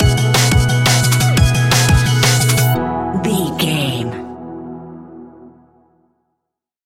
Ionian/Major
electronic
techno
trance
synths